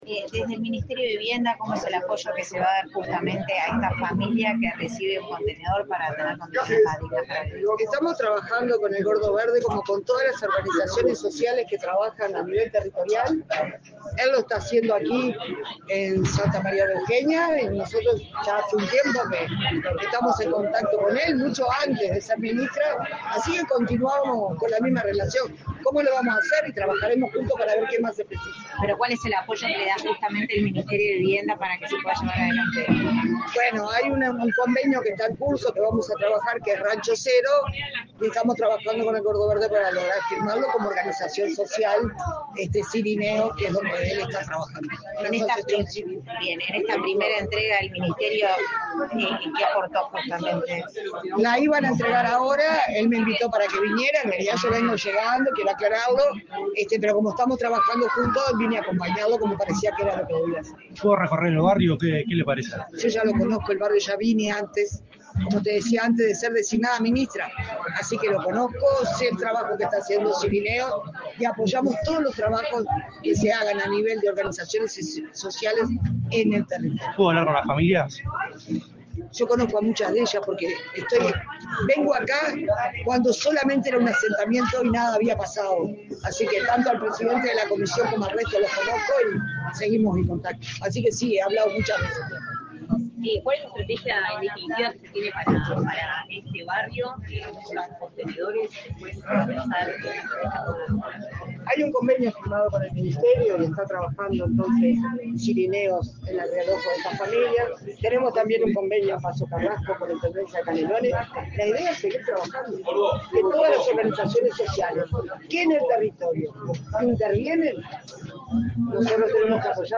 Declaraciones a la prensa de la ministra de Vivienda y Ordenamiento Territorial, Cecilia Cairo
Tras el encuentro, la jerarca realizó declaraciones a la prensa.